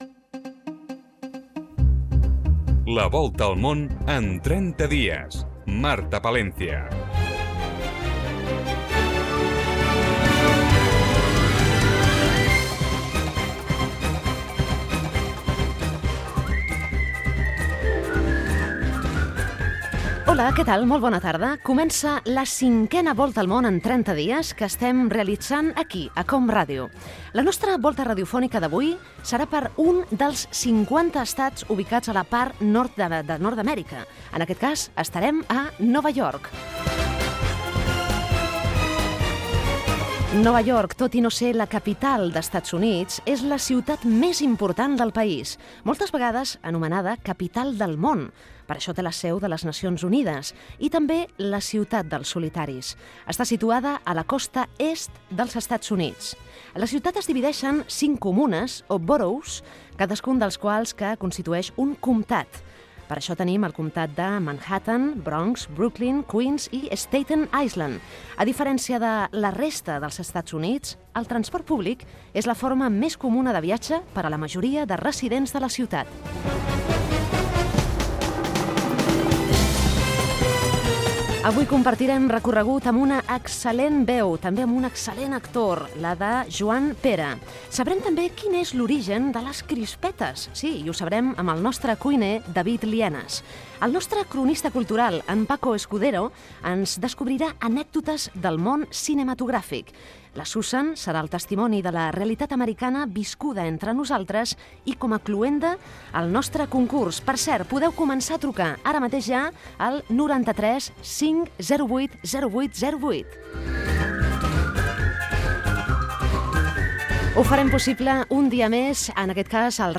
Inici del programa dedicat a la ciutat de Nova York. Crèdits. Conversa amb l'actor Joan Pera.
Divulgació